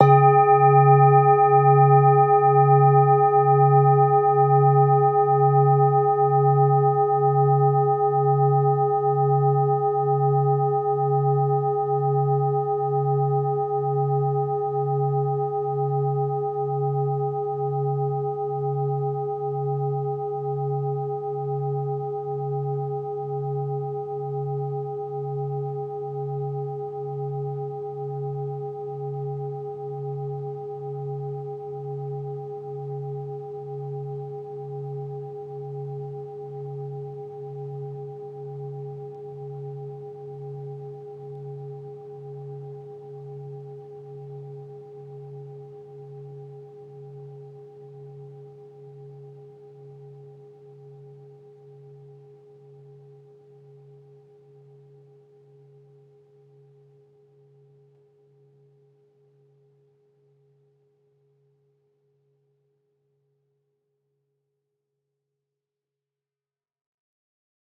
jan-bowl4-medium-C#2-mf.wav